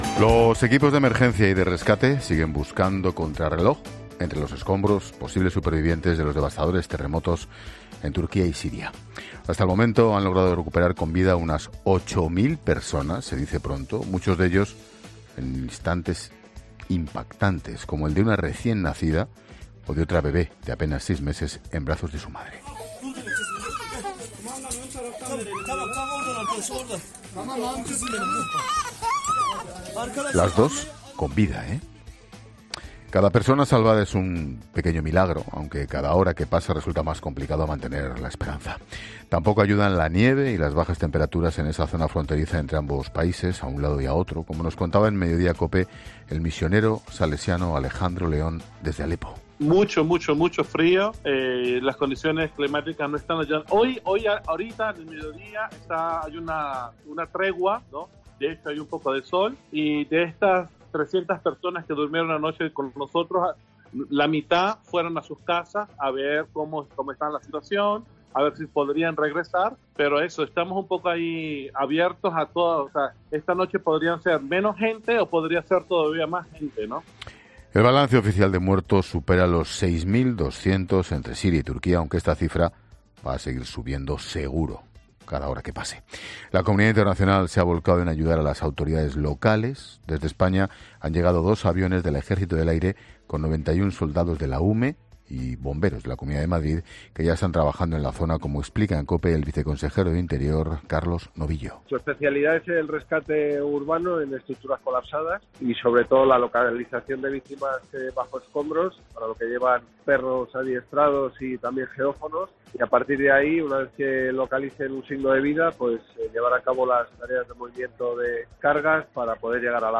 Ángel Expósito, informa sobre el terremoto en Turquía.